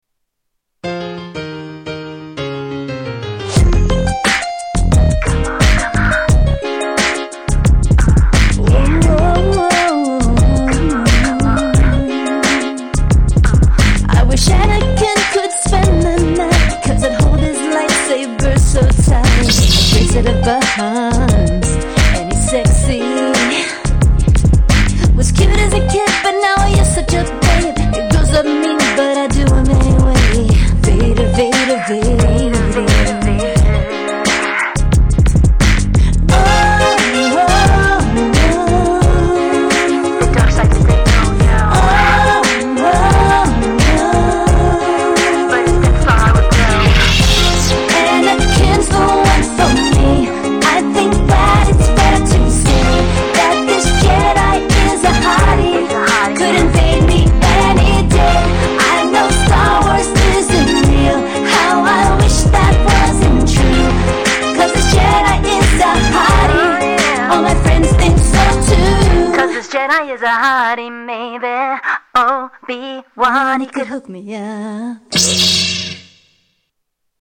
Tags: Media More Parodies Clips Parodies Songs Comedy Spoofs